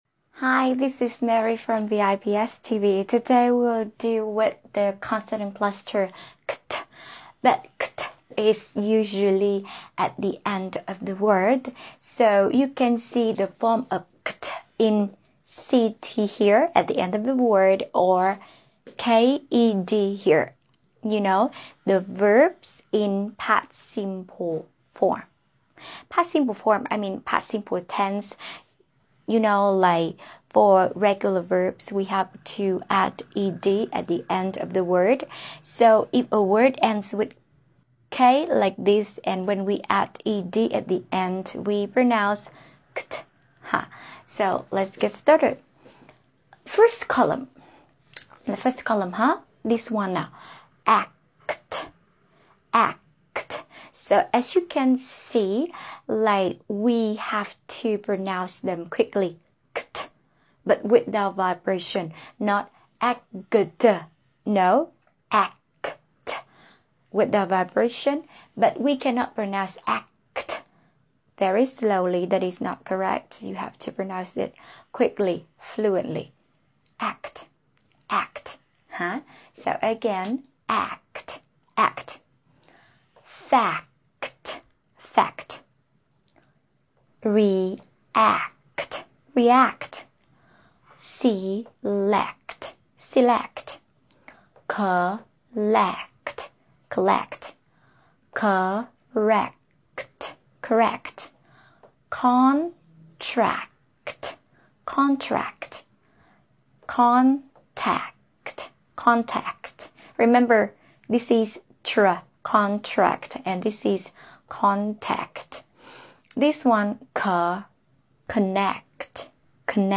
How to pronounce kt sound in English | consonant blend - consonant cluster | IPA